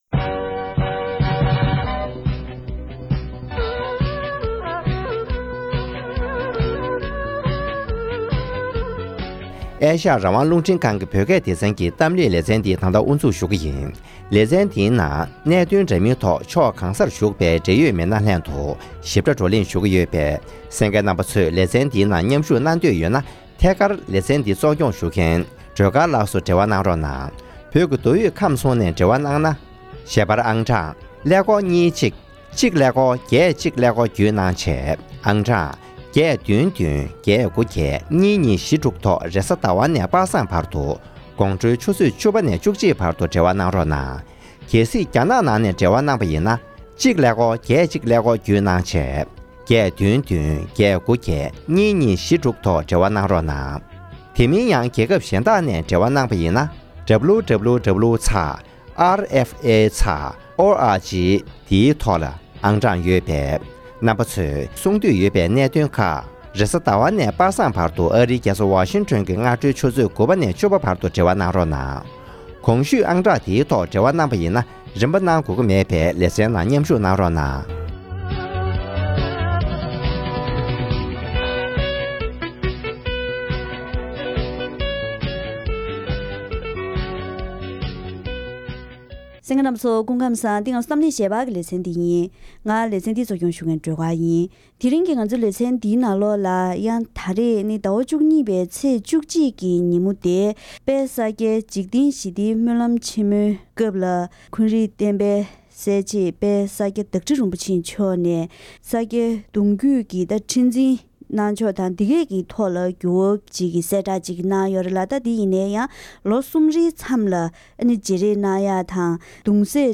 ༄༅། །དེ་རིང་གི་གཏམ་གླེང་ཞལ་པར་ལེ་ཚན་འདིའི་ནང་ས་སྐྱའི་སྨོན་ལམ་ཆེན་མོའི་སྐབས་ས་སྐྱ་བདག་ཁྲི་རིན་པོ་ཆེ་མཆོག་གིས་གདུང་རྒྱུད་ཀྱི་ཁྲི་འཛིན་གནང་ཕྱོགས་ཐད་བསྒྱུར་བ་བཏང་གནང་བའི་ཞིབ་ཕྲའི་གནས་ཚུལ་ཁག་ངོ་སྤྲོད་ཞུས་པ་ཞིག་གསན་རོགས་གནང་།།